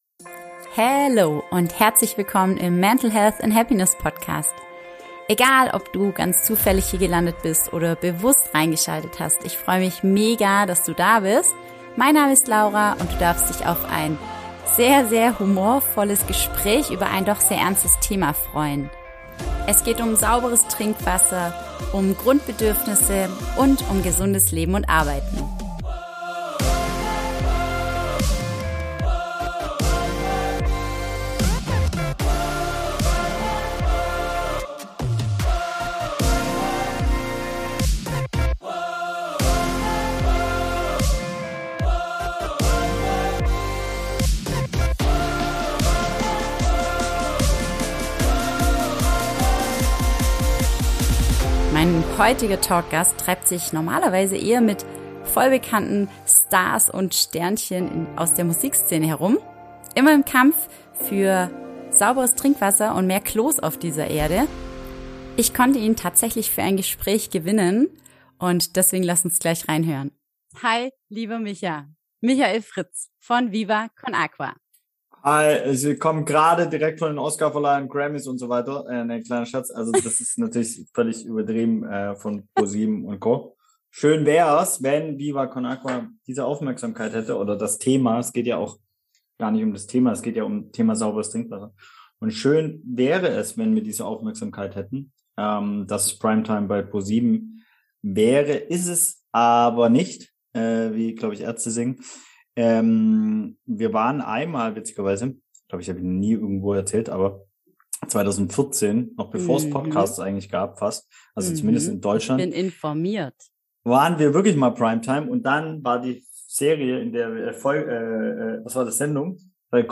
Wir lästern und lachen in diesem Gespräch nicht zu knapp, obwohl wir doch über so ernste Themen sprechen.